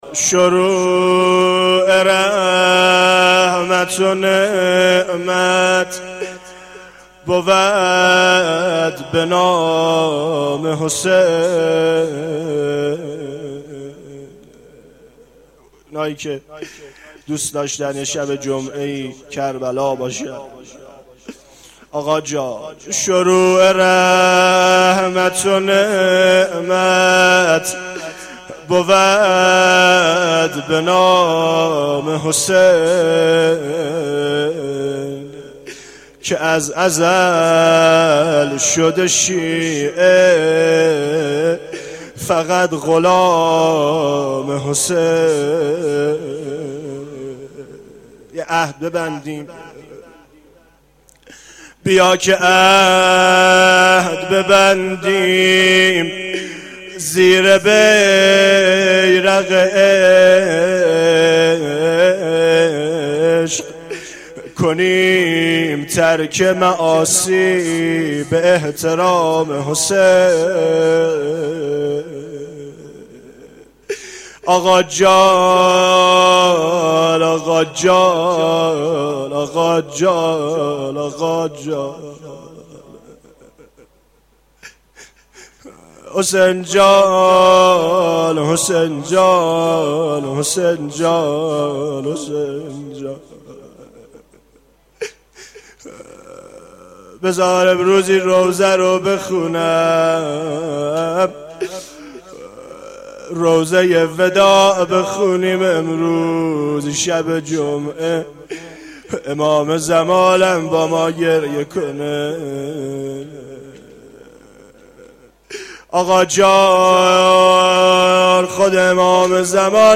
روضه4
روضه-4.mp3